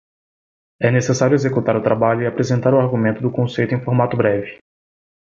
Uitgesproken als (IPA)
/kõˈsej.tu/